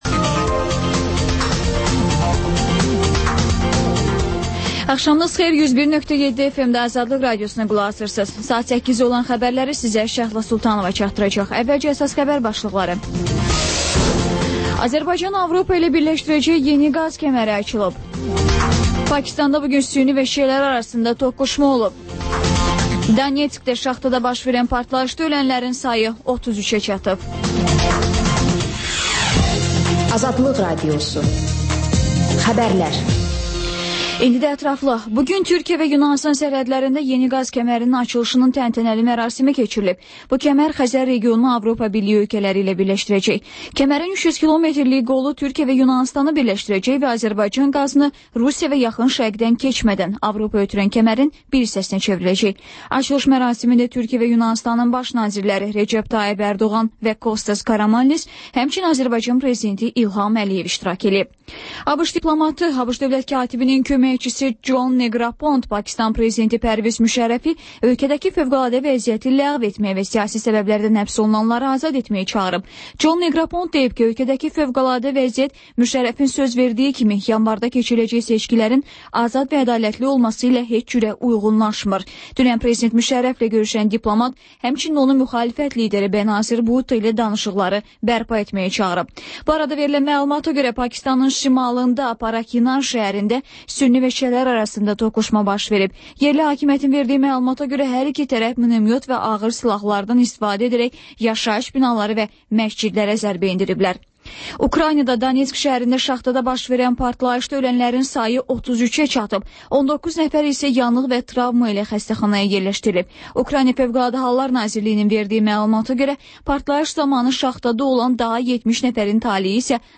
Xəbərlər, İZ: Mədəniyyət proqramı və TANINMIŞLAR rubrikası: Ölkənin tanınmış simalarıyla söhbət